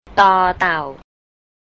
tor tao
table (low tone)